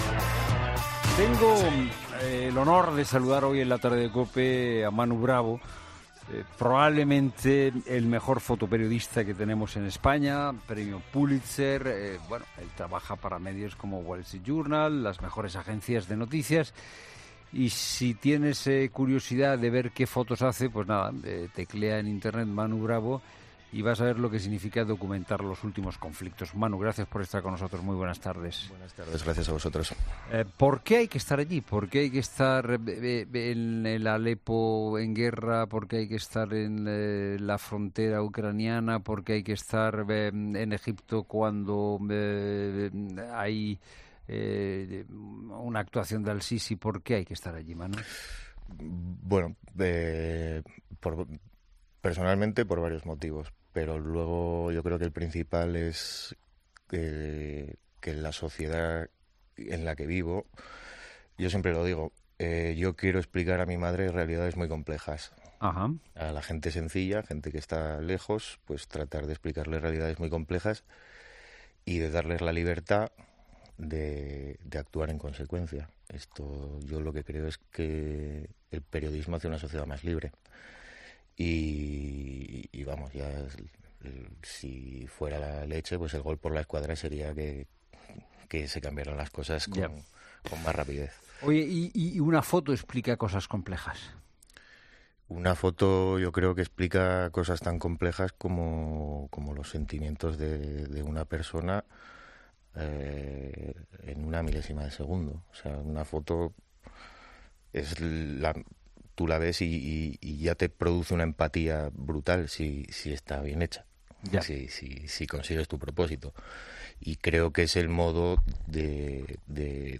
AUDIO: El fotoperiodista ha estado en La Tarde explicando cómo es su trabajo en zonas de conflictos